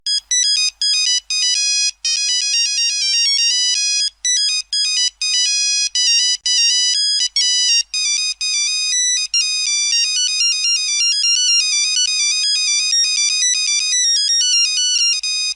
15. Звуки старого телефона звонок 5